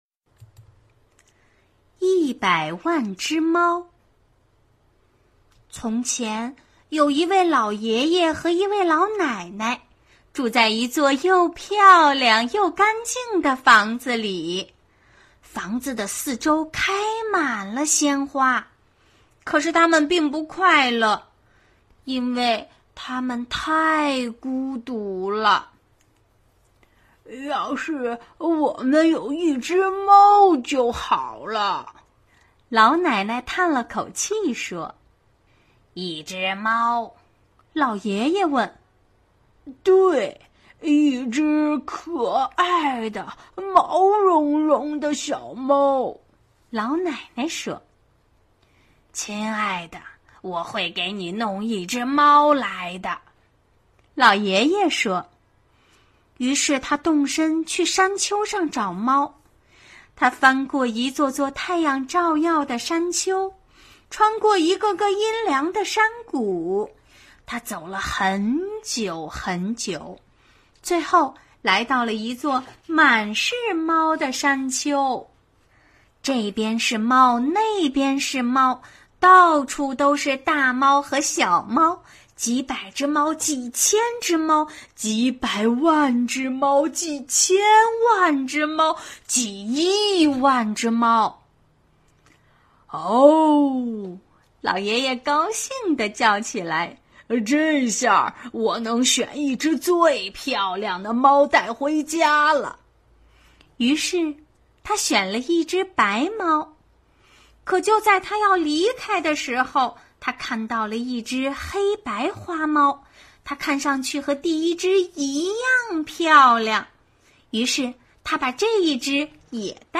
绘本故事 | 《100万只猫》